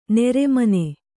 ♪ nere mane